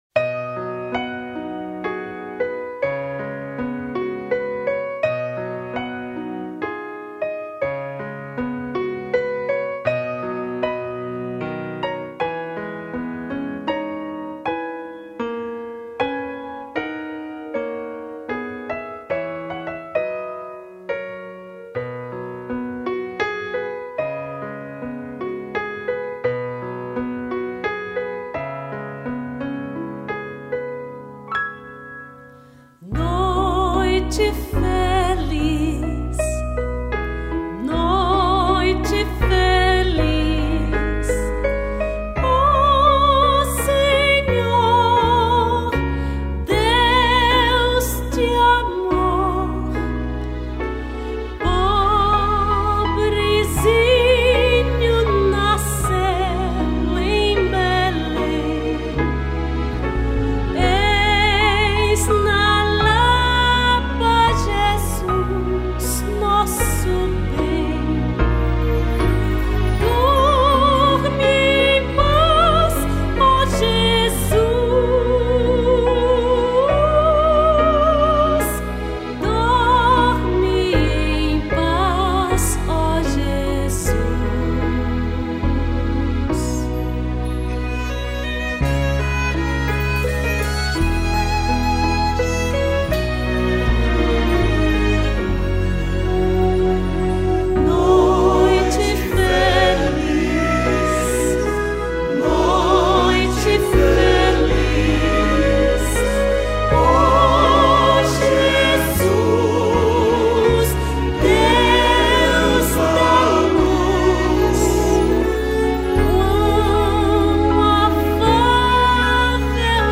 1044   03:20:00   Faixa:     Canção Religiosa